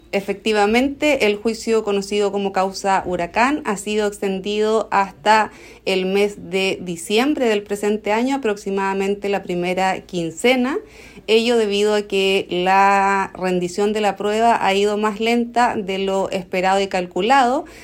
El nuevo plazo fue confirmado por la presidenta de la sala, jueza Rocío Pinilla, quien explicó los fundamento que les impulsaron a adoptar esta decisión